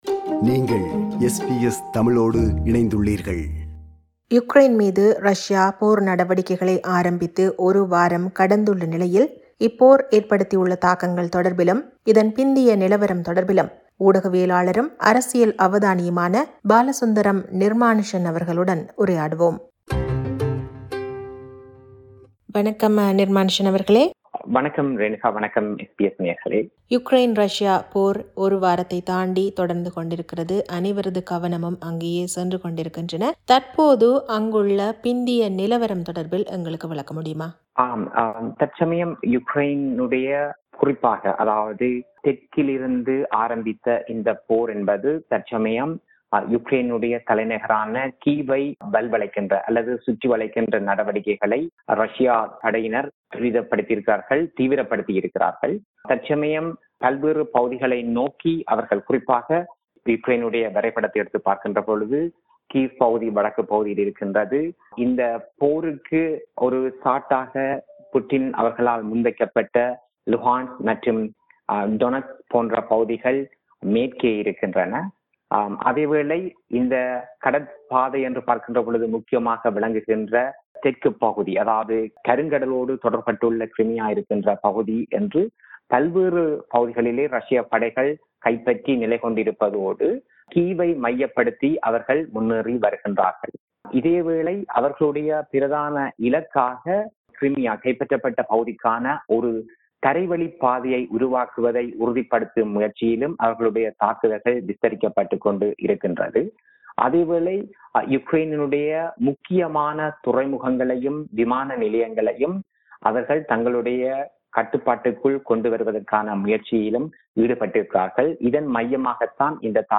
Listen to SBS Tamil at 8pm on Mondays, Wednesdays, Fridays and Sundays on SBS Radio 2.